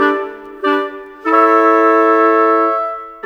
Rock-Pop 06 Winds 05.wav